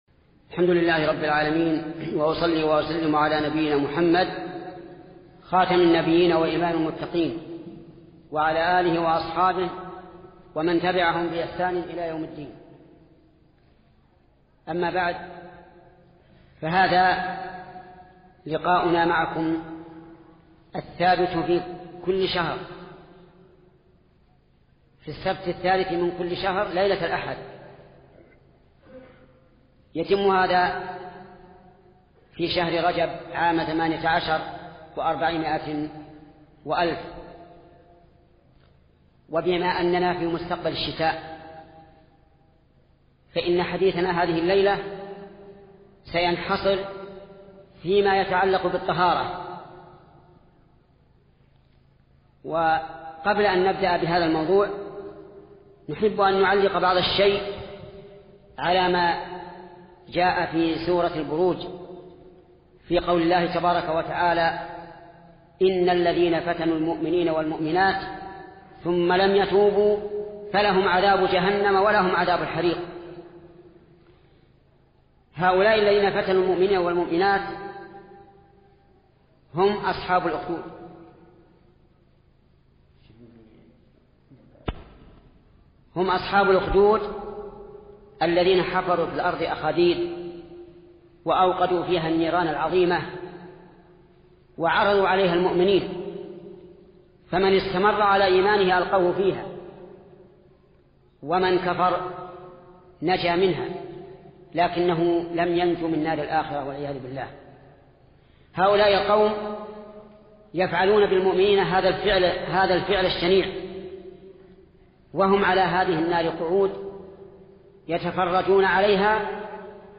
محاضره لشيخ محمد بن صالخ العثيمين بعنوان شروط التوبة